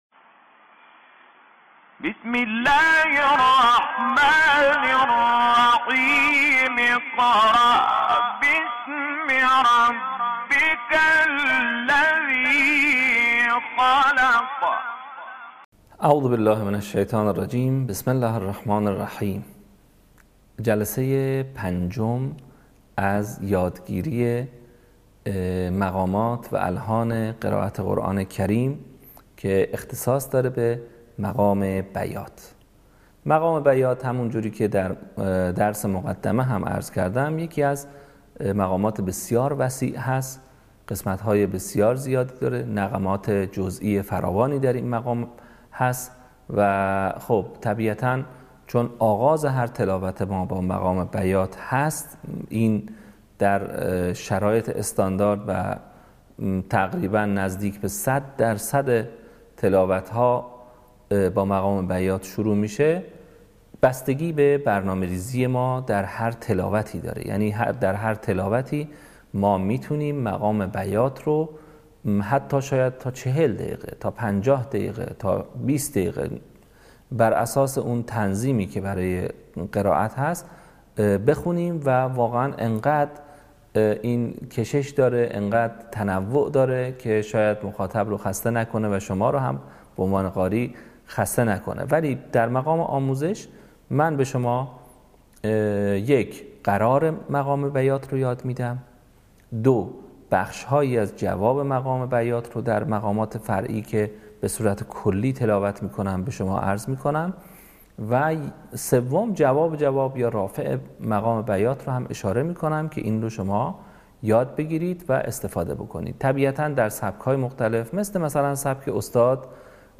صوت | آموزش مقام بیات